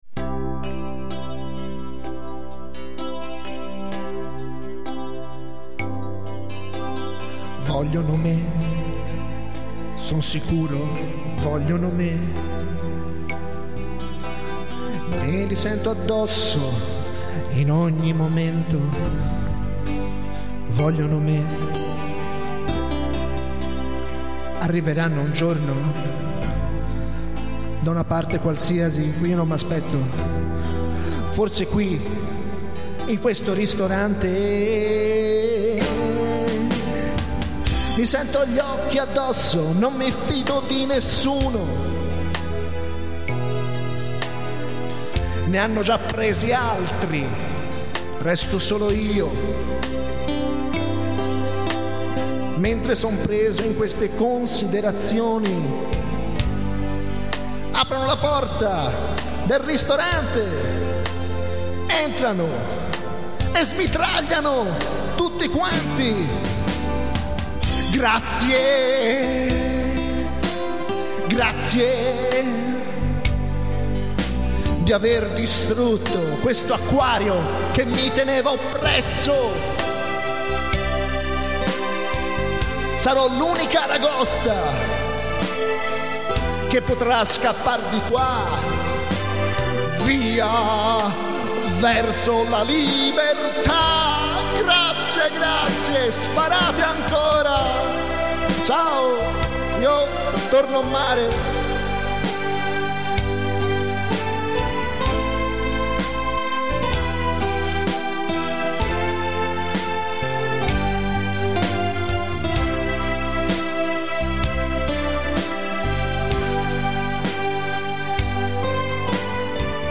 un pezzo pieno di suspence, alla tastiera